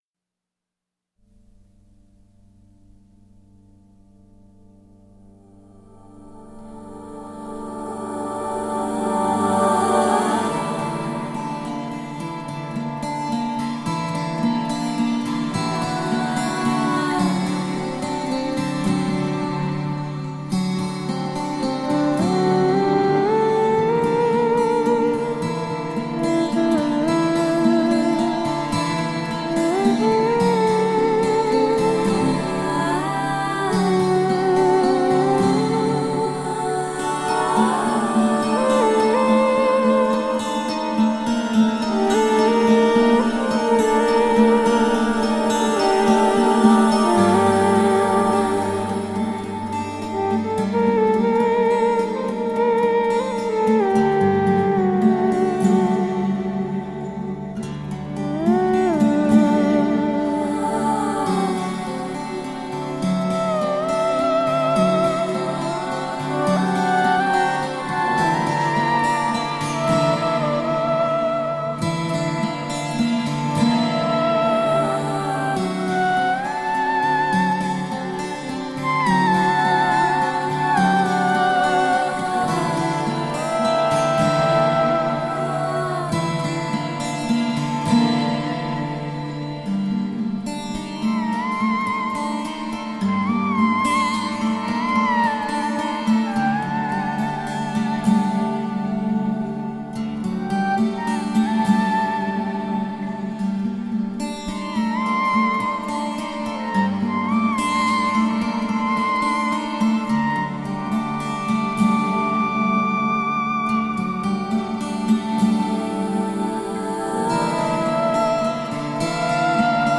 Beautiful ambient space music.
Tagged as: Ambient, New Age, Space Music